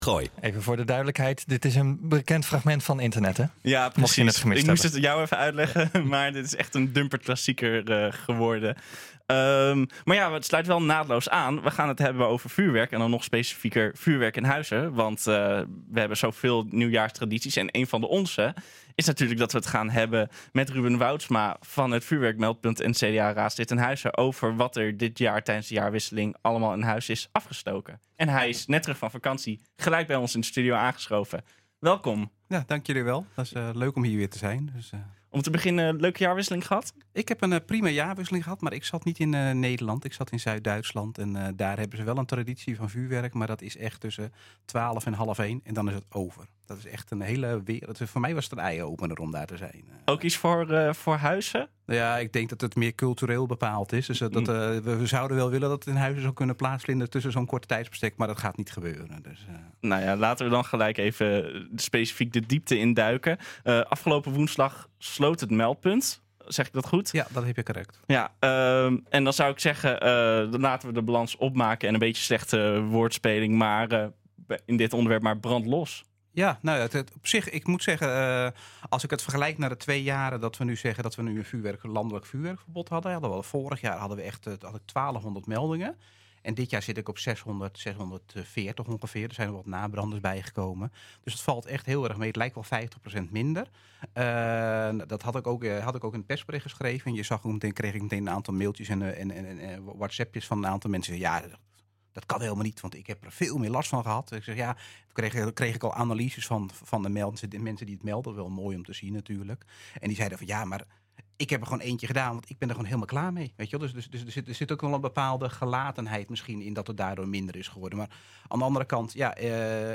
'Grappig om te zien', reageert Ruben Woudsma van het meldpunt in het radioprogramma NH Gooi Zaterdag.